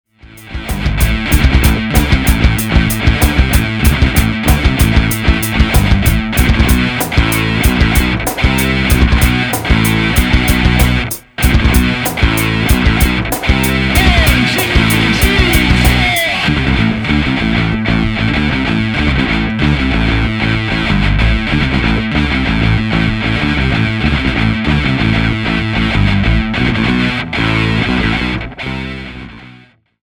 A play-along track in the style of Rock and Metal.